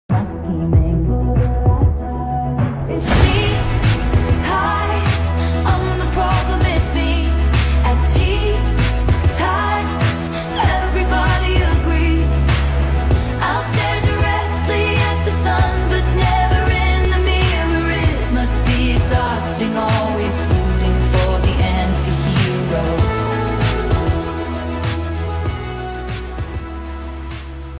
sad edit